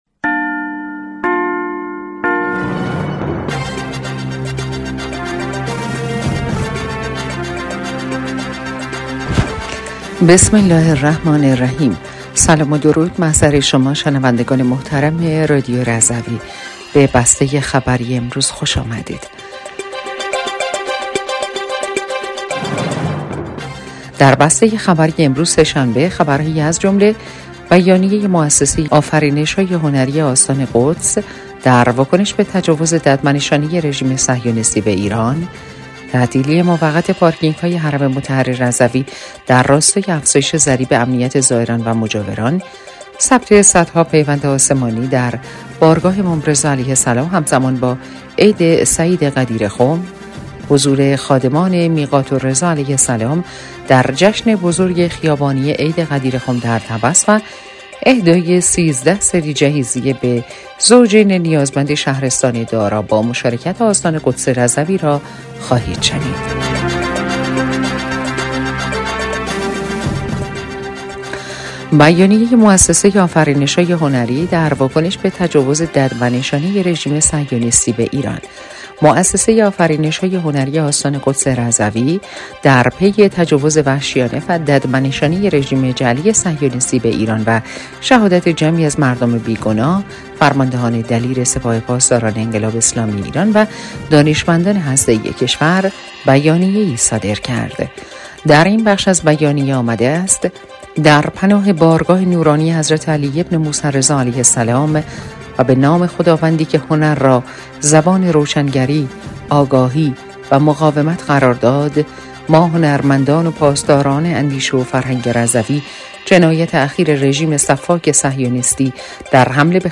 بسته خبری ۲۷ خردادماه ۱۴۰۴ رادیو رضوی/